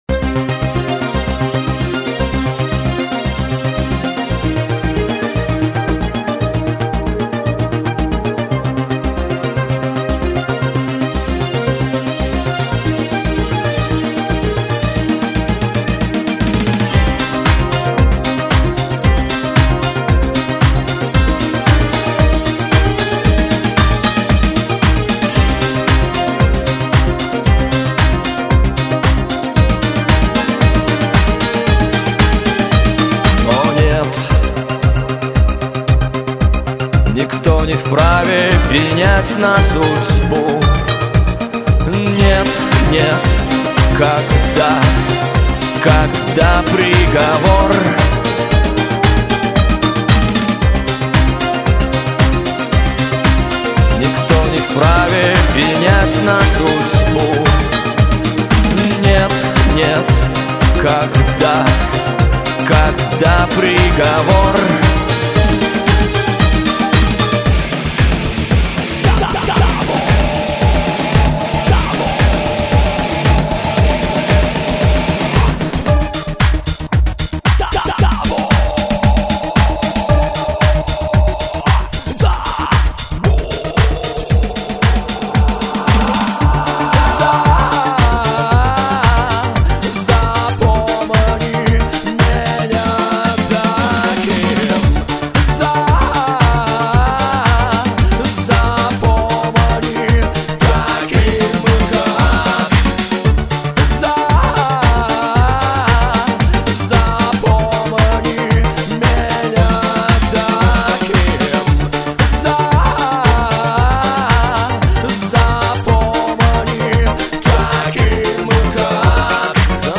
Тема на зацен, engl e530, superior 2.0, math inside, немнога вокала
Вокал правда не везде где надо записал.
Барабасы - Superior 2.0, попробовал нарулить шоб звучало.
Нажмите для раскрытия... c преампа в линию, потом месовские импульсы. Нижняя середина как раз почти в минимуме на преампе.